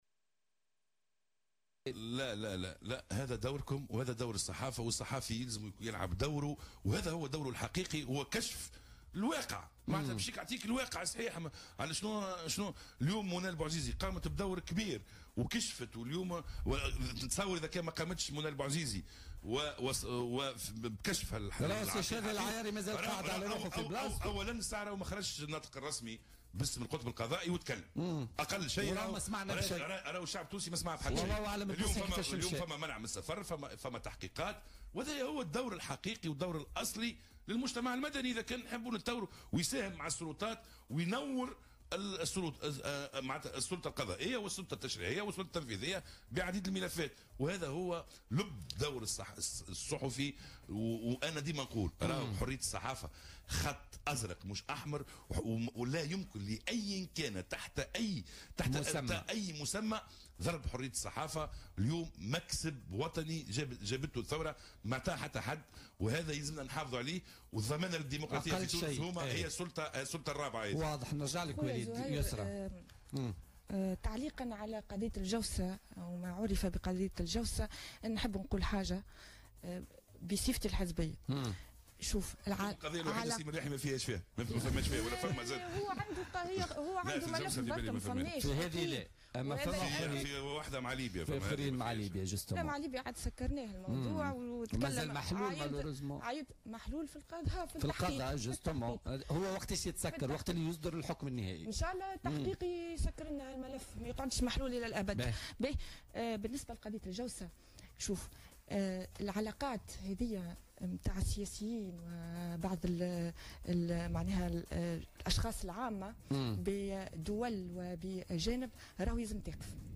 أكد النائب وليد جلاد ضيف بولتيكا اليوم الإثنين 19 فيفري 2018 أن الصحفي يجب أن يلعب دوره الحقيقي المثتمثل في كشف الحقيقي مضيفا أنه لو لم يتم نشر التحقيق الذي تحدث عن قضية جوسسة وغسيل اموال في تونس لما تم فتح هذا الملف.